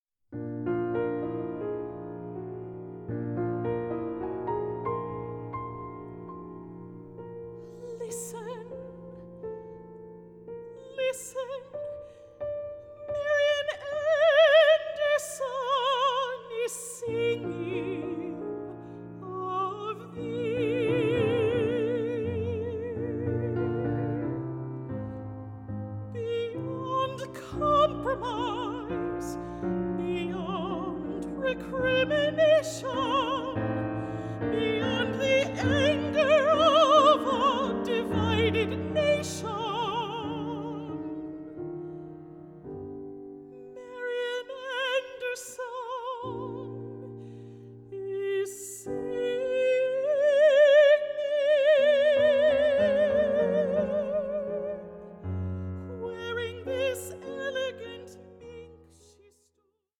mezzo-soprano
with the composer at the piano.